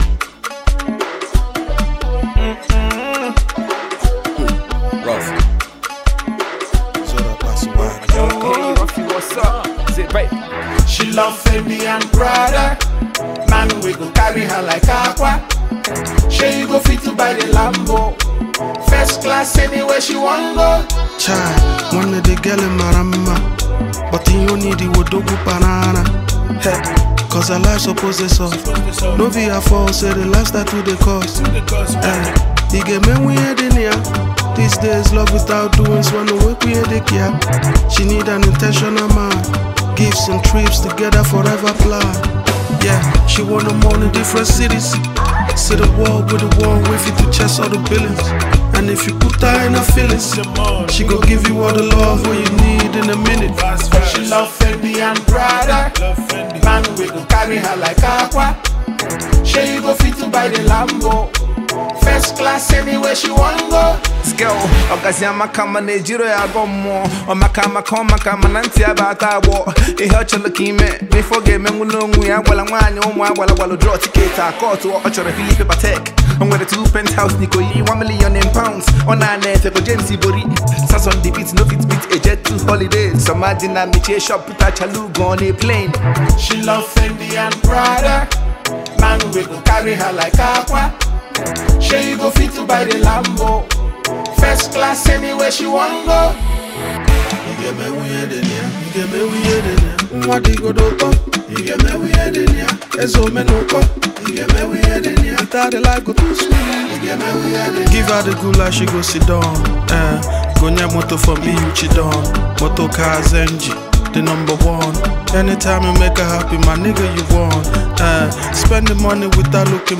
a fresh banger that hits with pure Eastern energy!